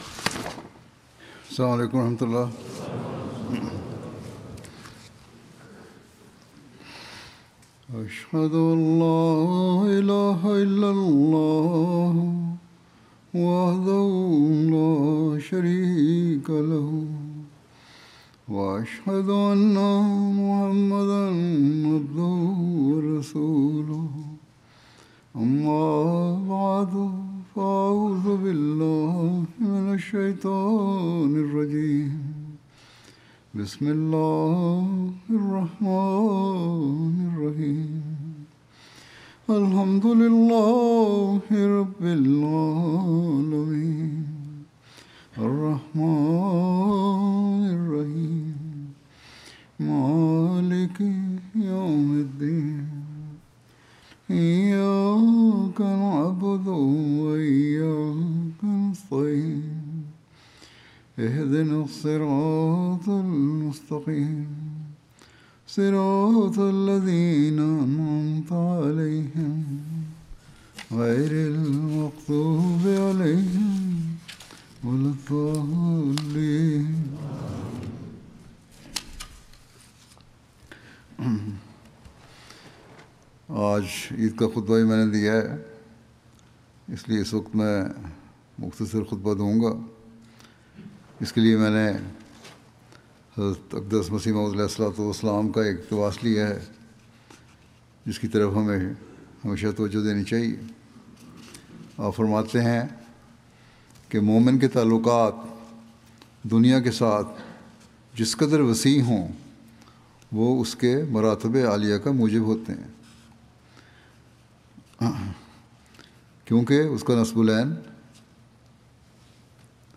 20 March 2026 The Promised Messiah (as): A Momin's Hallmark - World Becomes Subservient to Faith Urdu Friday Sermon by Head of Ahmadiyya Muslim Community 9 min About Urdu Friday Sermon delivered by Khalifa-tul-Masih on March 20th, 2026 (audio)